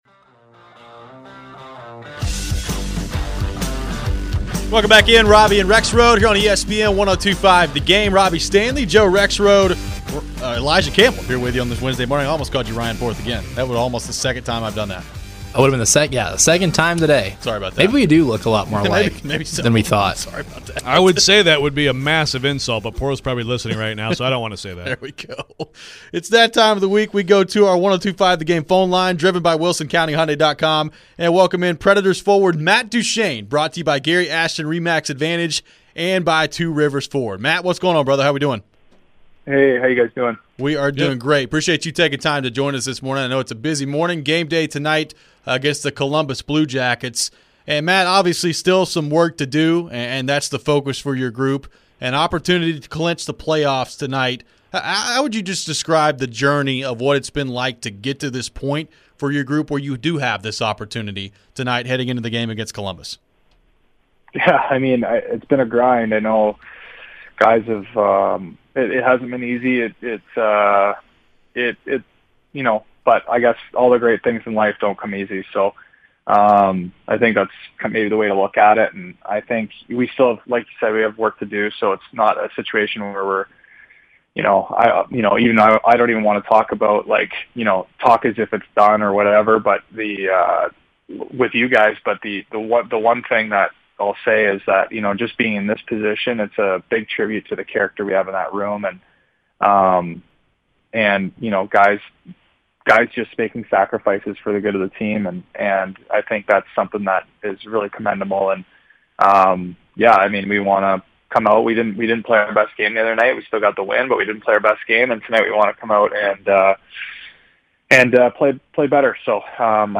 Nashville Predators forward Matt Duchene joined the show to discuss the big game in Columbus, the impact of John Hynes on the season turnaround and more during his weekly visit!